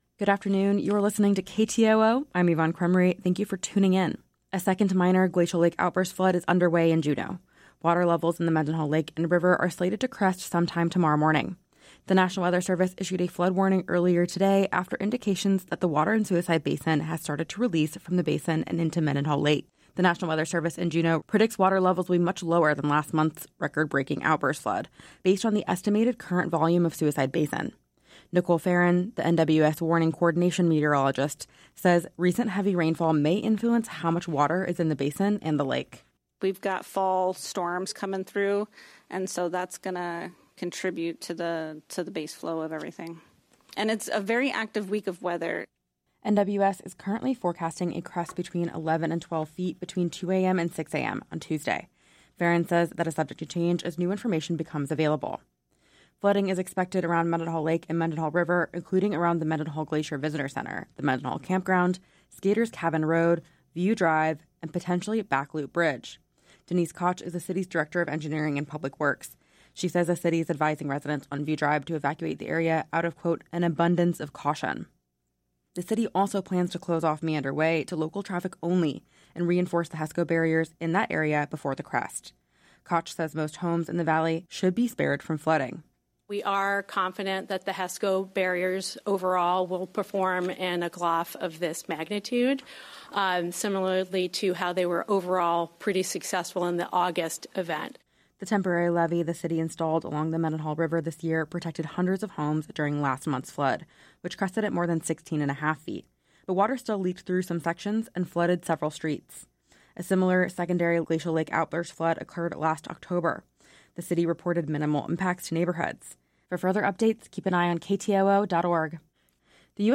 Newscast – Monday, Sept. 15, 2025 - Areyoupop